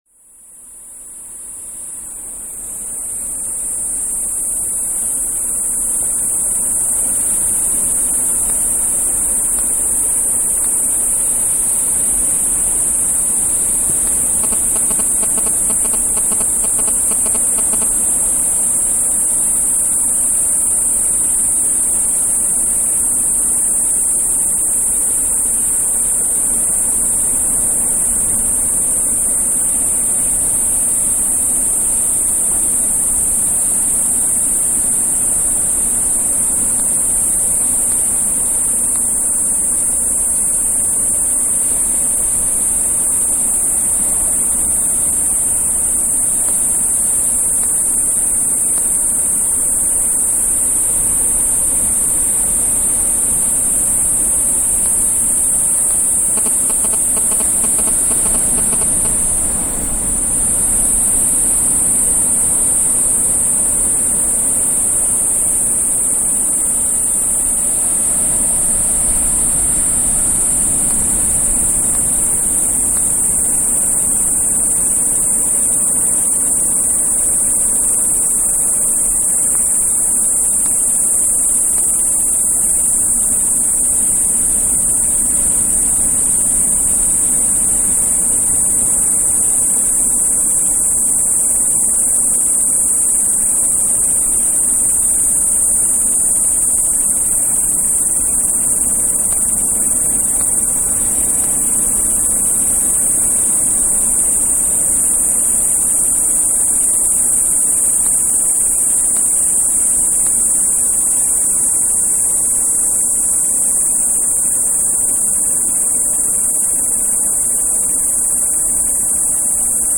Cicadas in Sandy Bay, Cape Town
Field recording from Cape Town, South Africa.